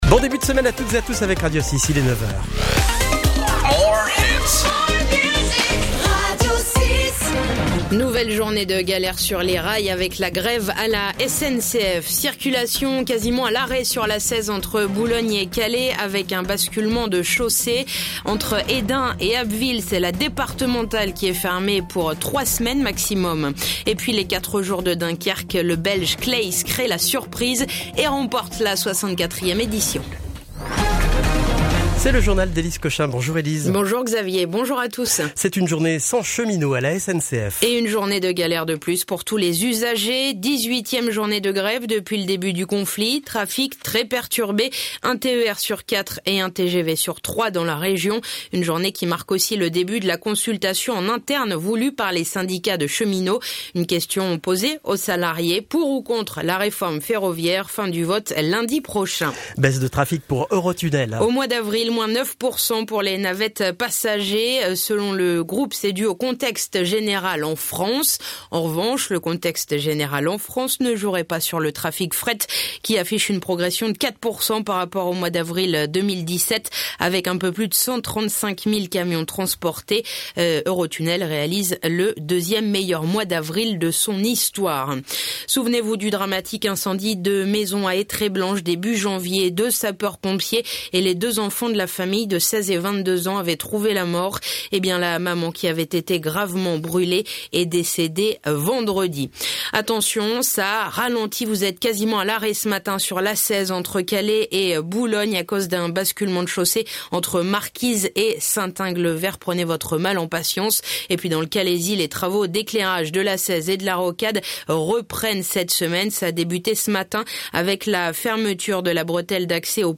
Le journal Côte d'Opale du lundi 14 mai